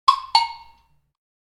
Tik-tok-sound-effect.mp3